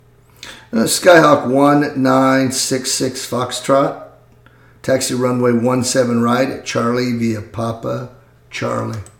Aviation Radio Calls
05_GroundRunwayOneSevenRightCharllie.mp3